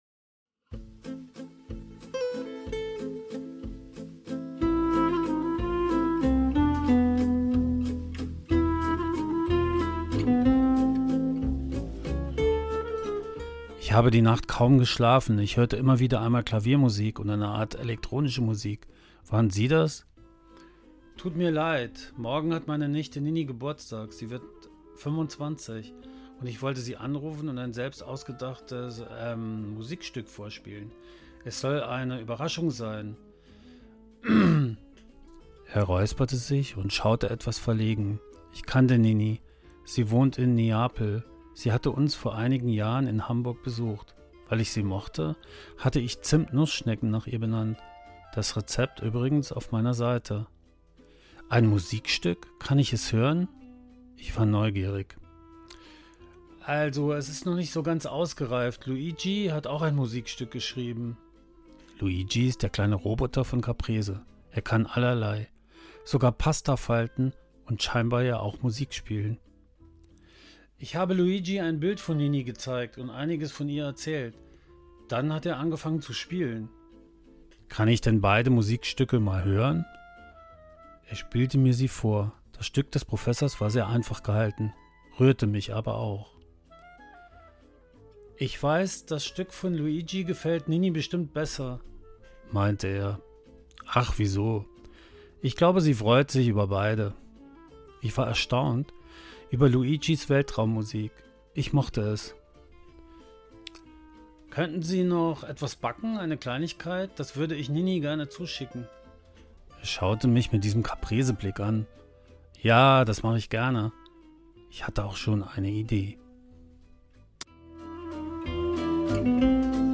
Geschichte